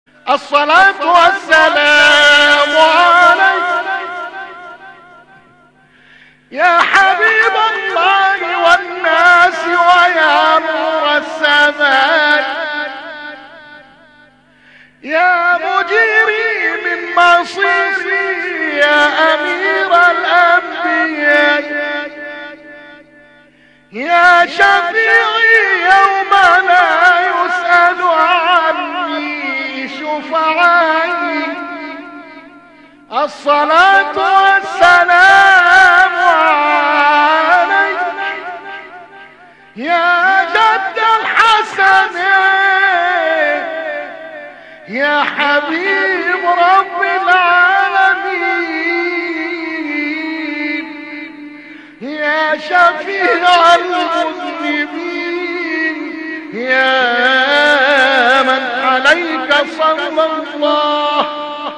تواشيح